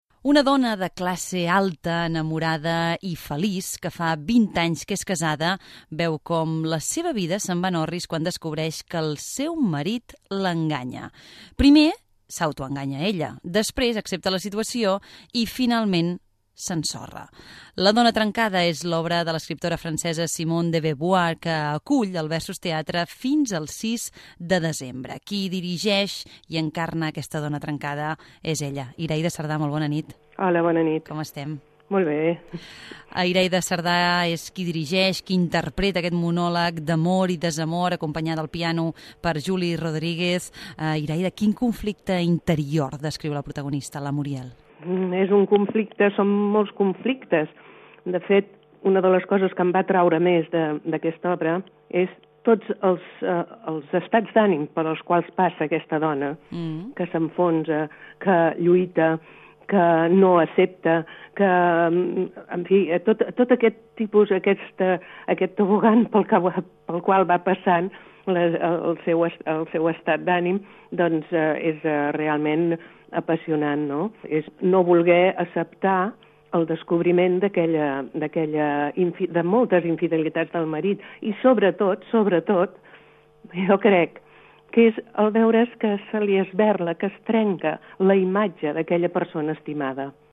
Language quiz